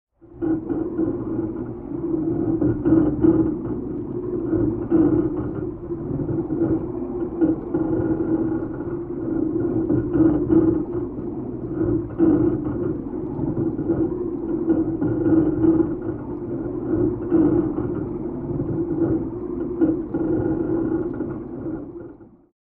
Hum, atmosphere of depth 8
Sound category: Water